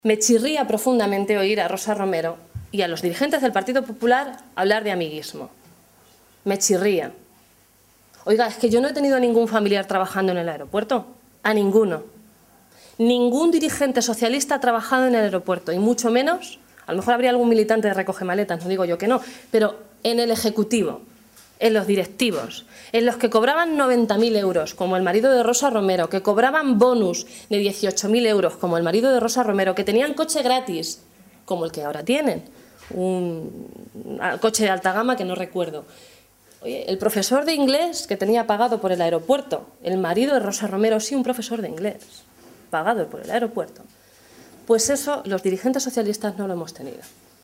Blanca Fernández en rueda de prensa